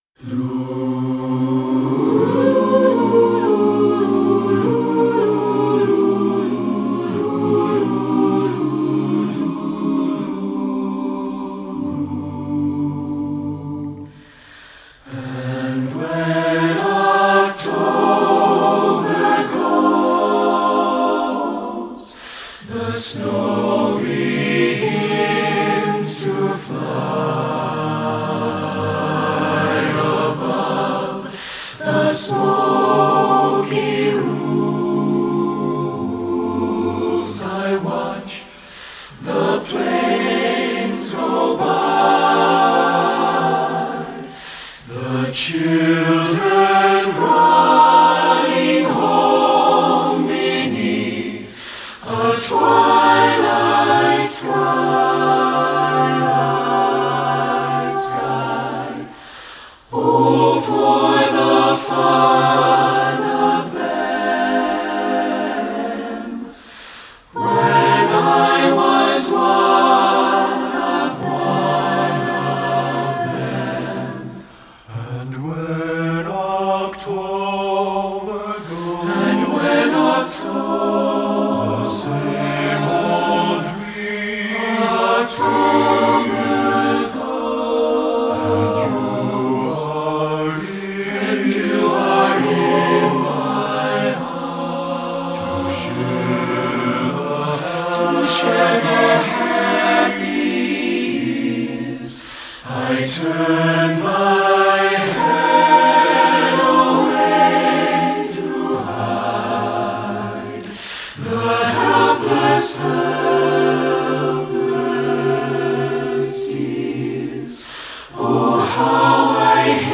Voicing: SSAA a cappella